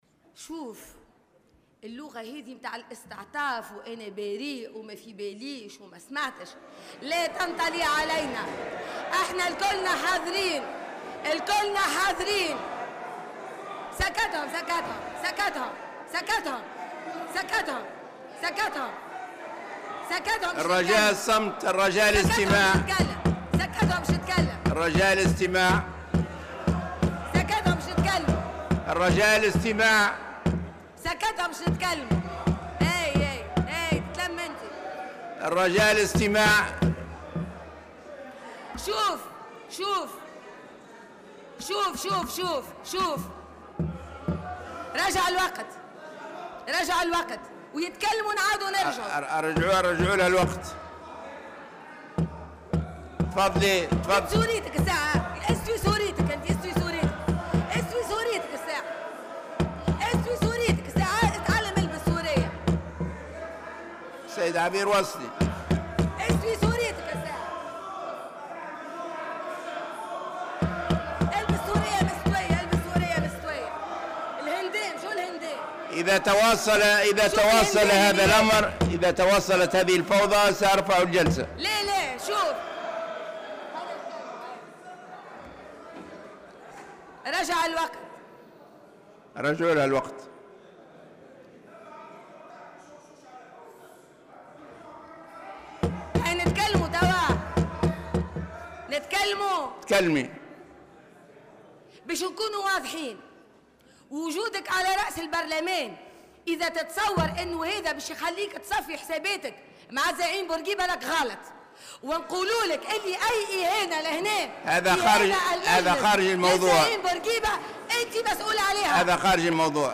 وأضافت موسي خلال الجلسة العامة التي انعقدت اليوم بمجلس النواب لمناقشة لائحة بخصوص مطالبة فرنسا بالاعتذار من الشعب التونسي : " لغة ما في باليش وما سمعتش لا تنطلي علينا..ووجودك في البرلمان ما يخليكش تصفي حساباتك مع بورقيبة وأي إهانة في المجلس للزعيم أنت المسؤول عنها".وطالبت موسي بالاعتذار عن هذه الإهانة للرئيس الراحل الحبيب بورقيبة وسحبها من مداولات الجلسة.